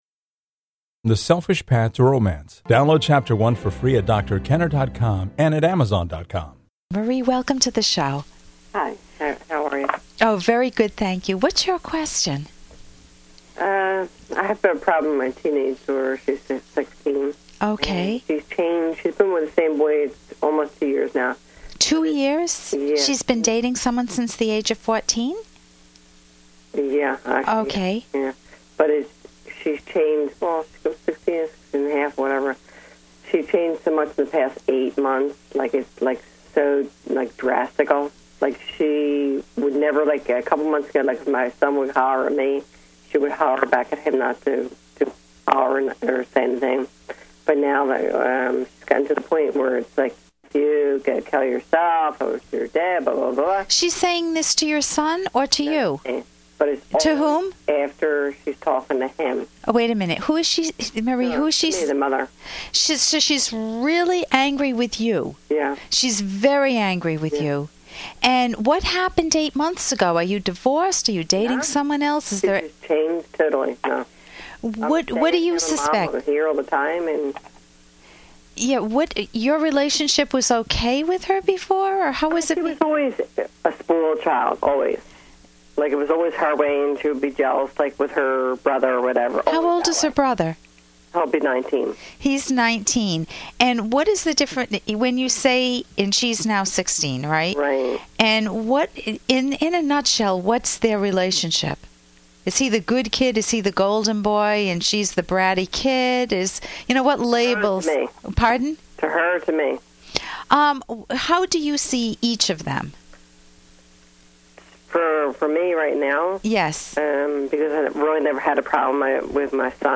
The Rational Basis of Happiness® radio show